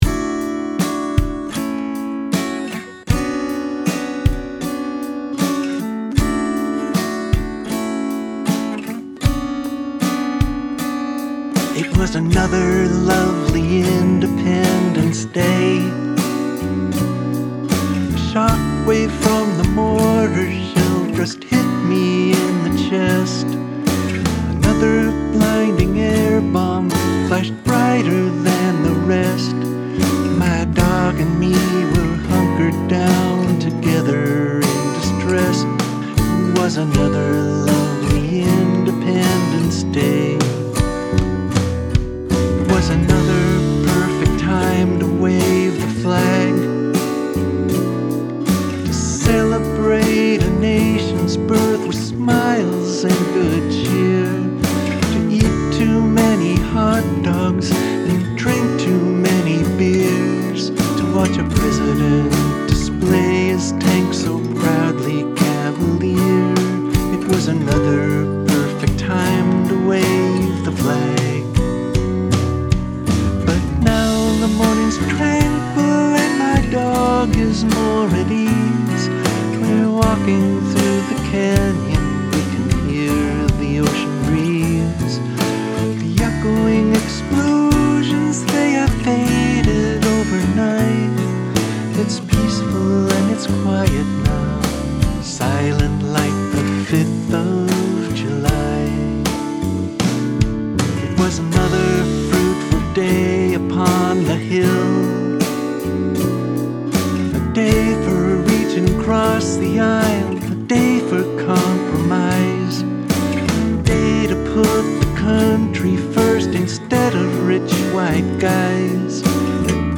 The Fifth of July mastered in Logic.mp3